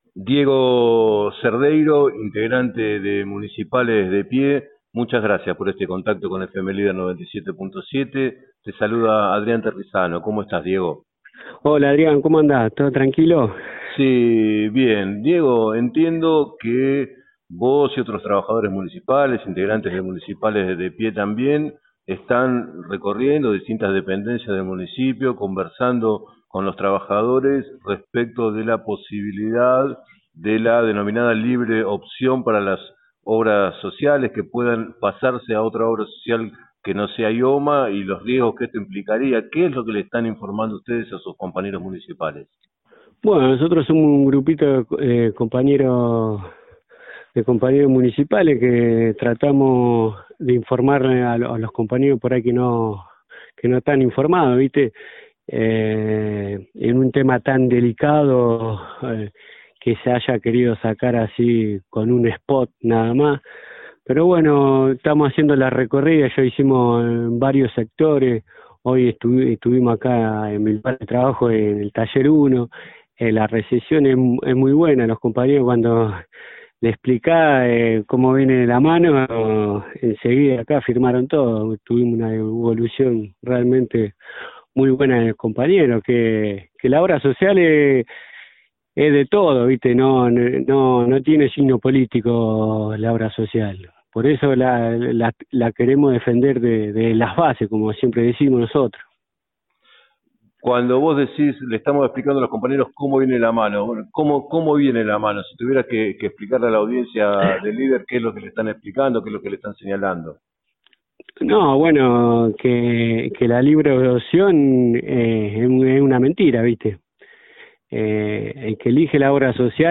En declaraciones al programa 7 a 9 de FM Líder 97.7